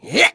Neraxis-Vox_Attack2.wav